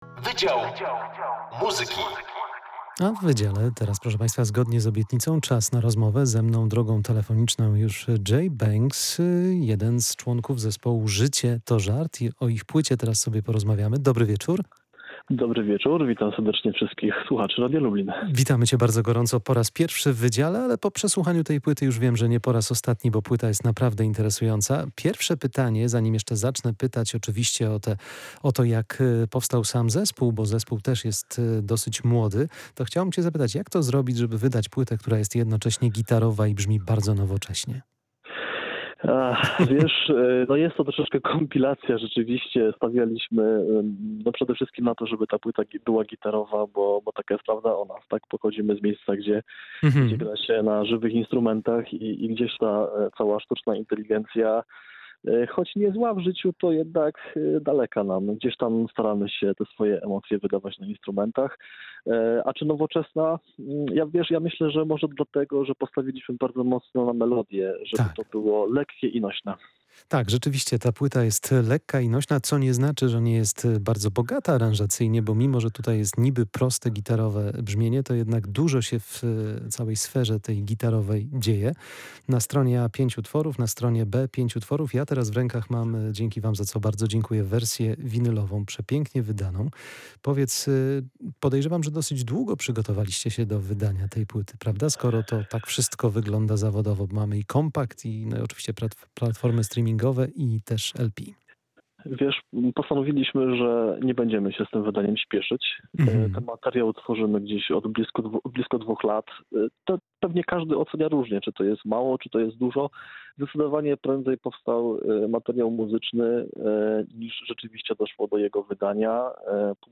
Wydział Muzyki: Życie to żart, czyli prawdziwa muzyka grana na prawdziwych instrumentach [POSŁUCHAJ ROZMOWY]